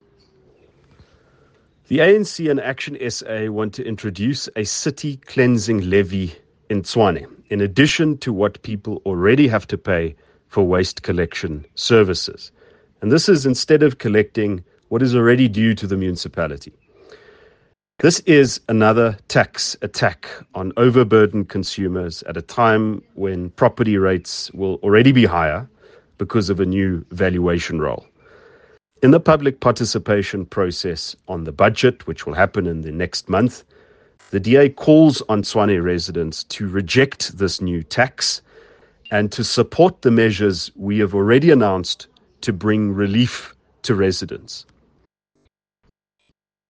Note to Editors: Please find attached English and Afrikaans soundbites by Ald Cilliers Brink